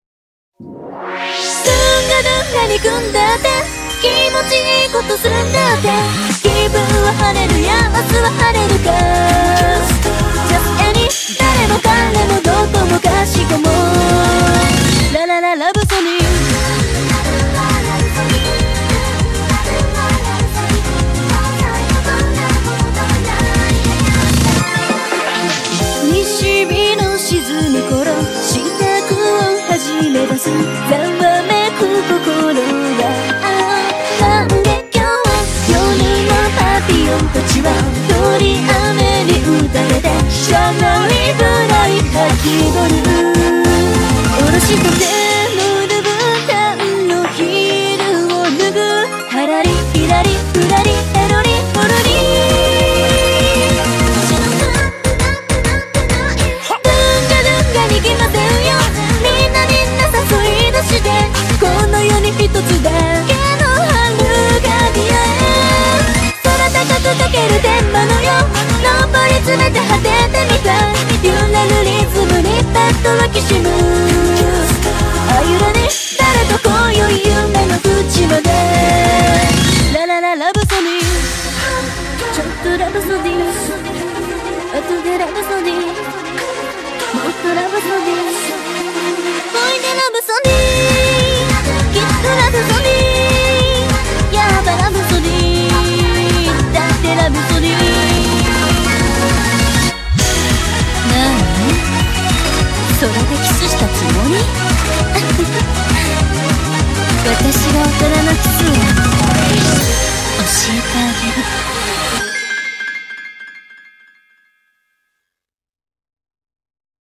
BPM200
Audio QualityPerfect (Low Quality)